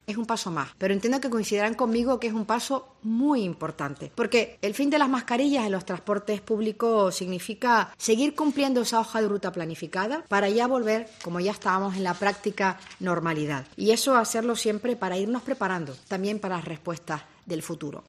La ministra de Sanidad Carolina Darias, sobre el fin de las mascarillas en el transporte: "Un paso importante